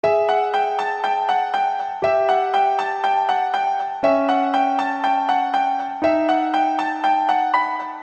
Sample 1: Out of key